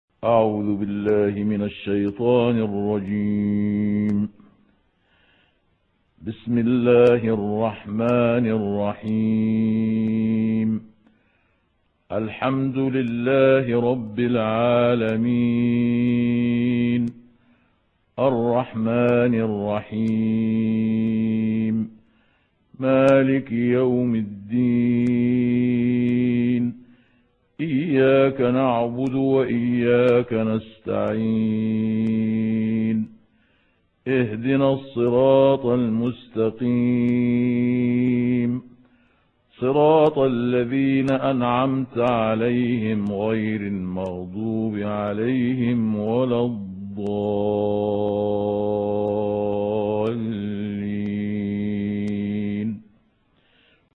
Riwayat Hafs